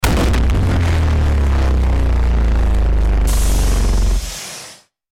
laserbig.mp3